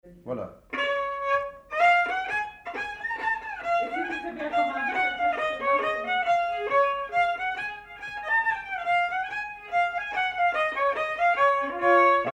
Mazurka partie 1
danse : mazurka
circonstance : bal, dancerie
Pièce musicale inédite